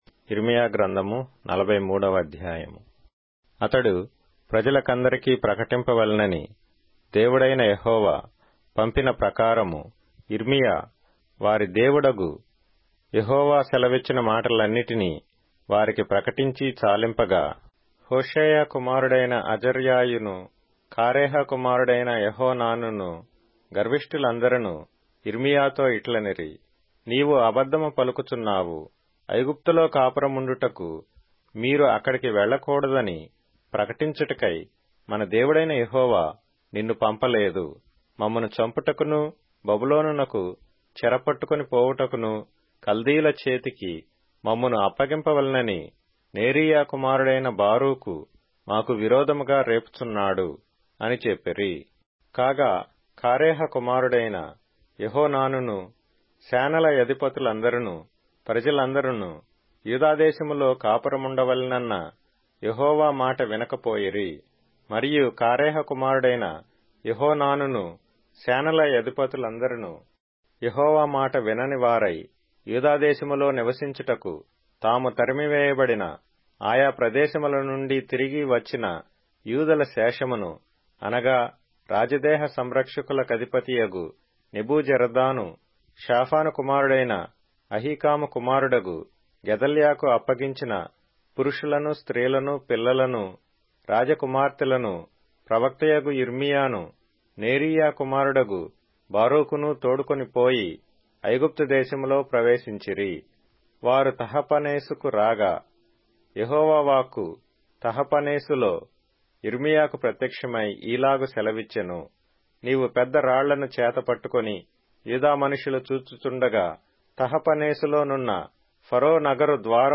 Telugu Audio Bible - Jeremiah 14 in Irvkn bible version